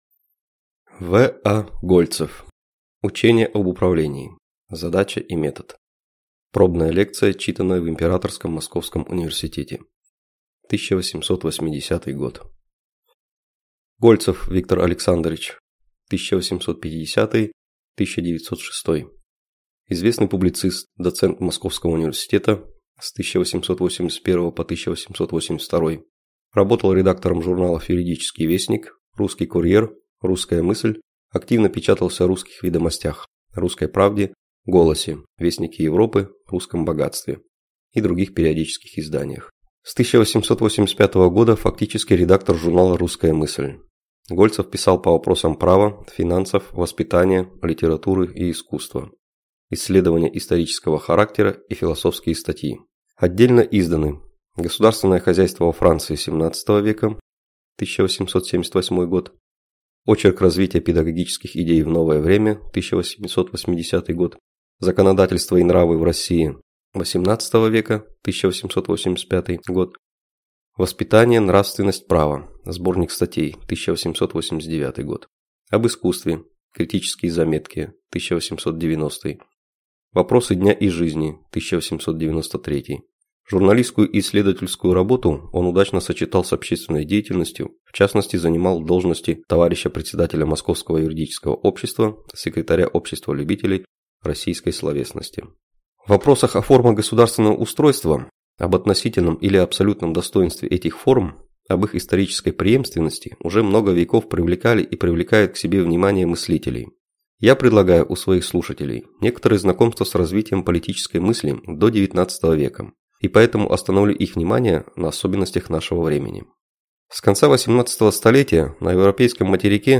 Аудиокнига Учение об управлении (задача и метод) (Пробная лекция, читанная в Императорском Московском Университете) | Библиотека аудиокниг